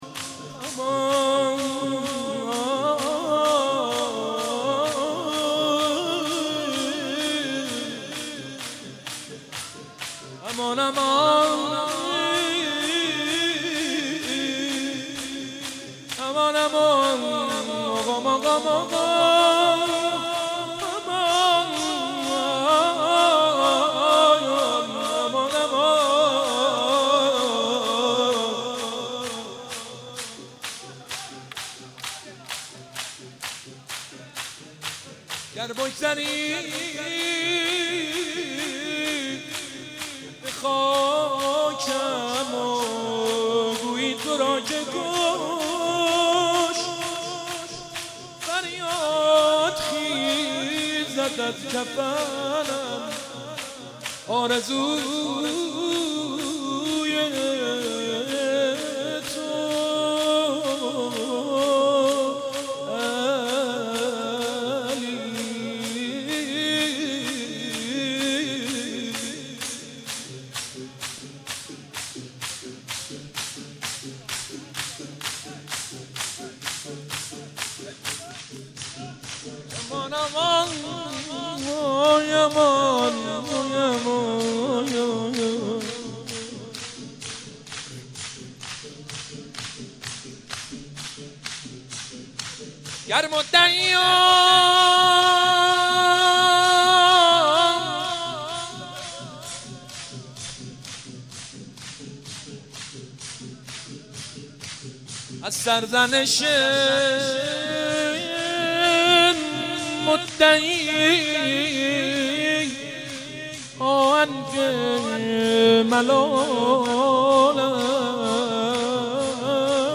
شب تاسوعا محرم95/هیئت خادم الرضا (ع) قم
شعرخوانی